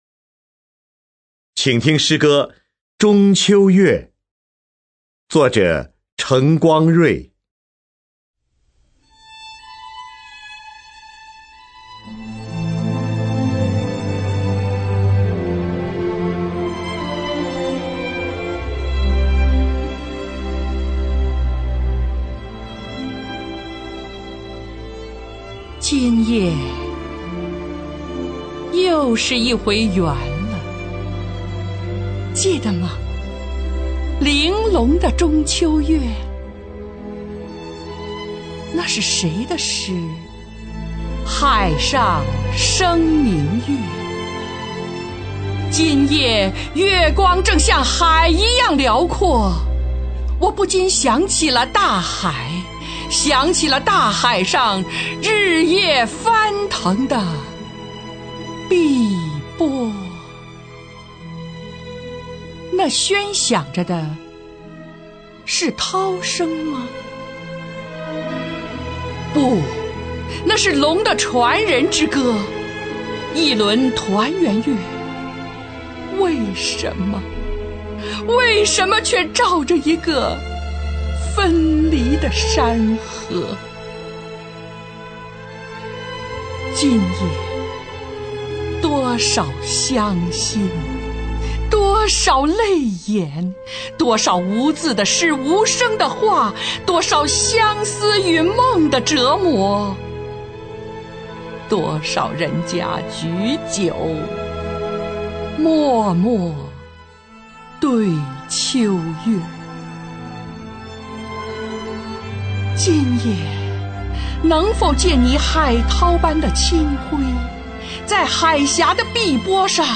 [24/9/2009]张筠英配乐朗诵程光锐的诗词《中秋月》
张筠英 朗诵